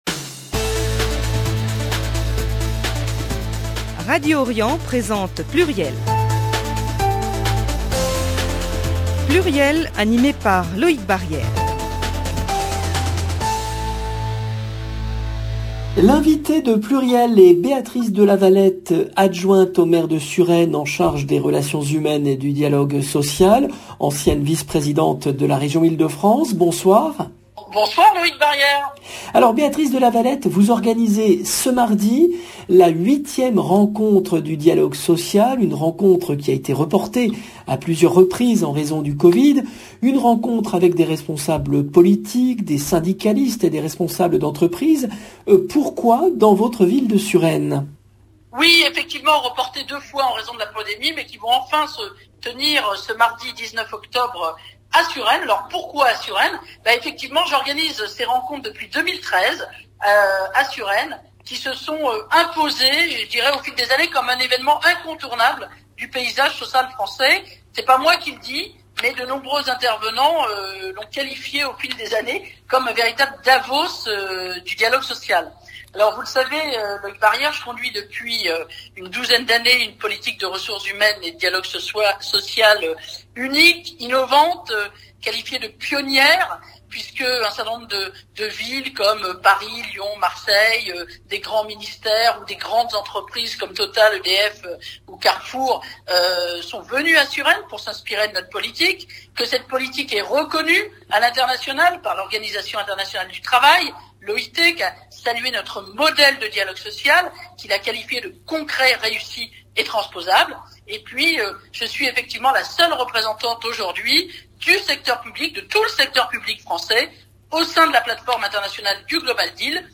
Béatrice de Lavalette, adjointe au Maire de Suresnes, invitée de Pluriel
Emission diffusée le lundi 18 octobre 2021